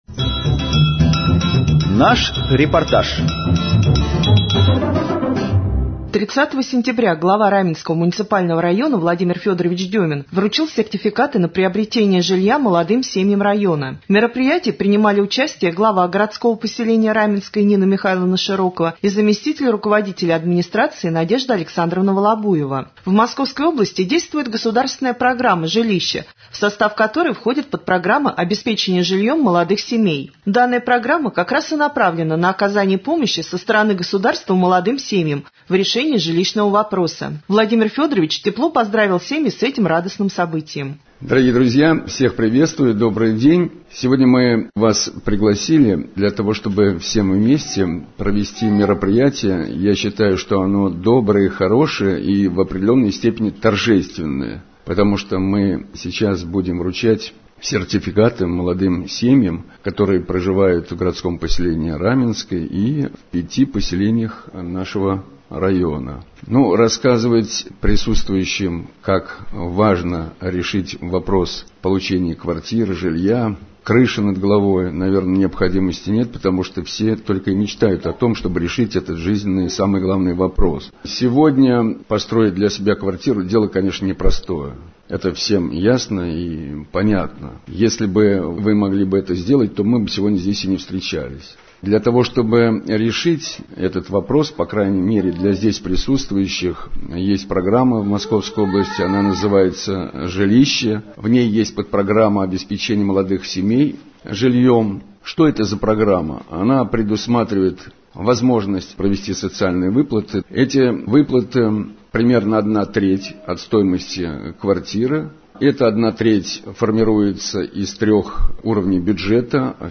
3.Рубрика «Специальный репортаж». 30 сентября глава района вручил сертификаты на приобретение жилья молодым семьям района.